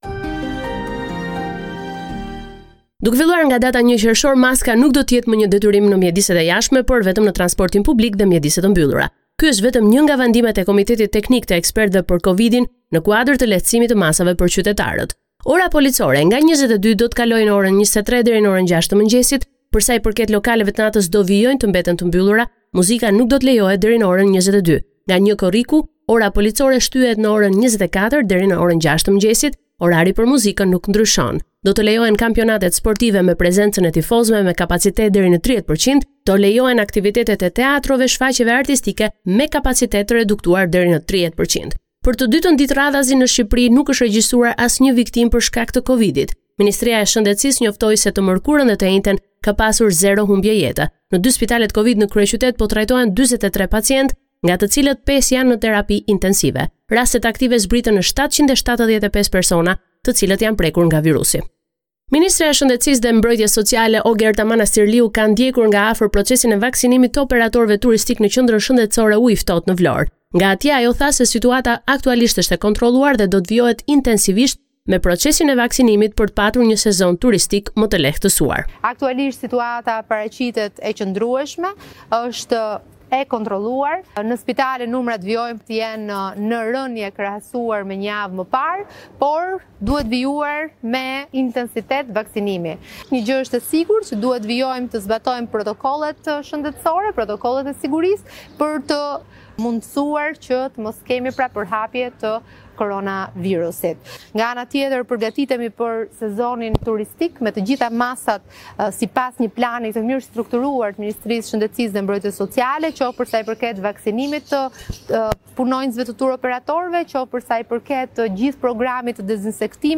Raporti me te rejat me te fundit nga Shqiperia.